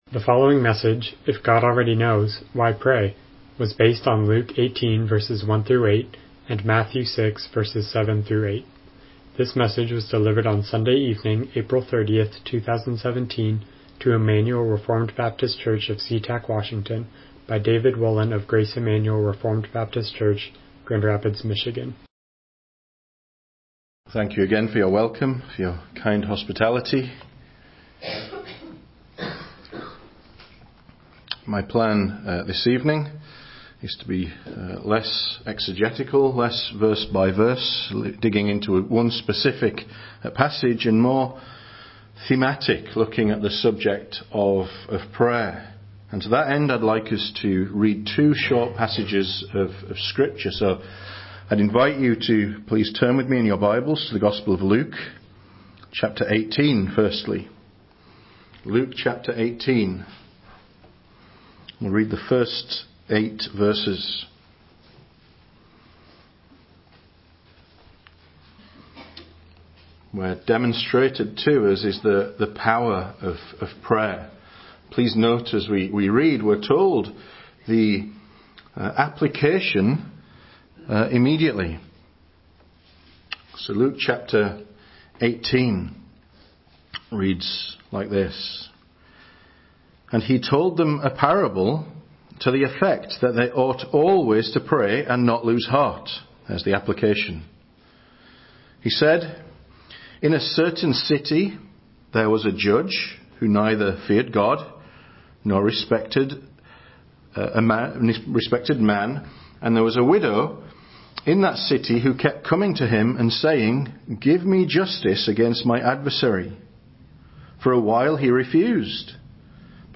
Matthew 6:7-8 Service Type: Evening Worship « Where Were You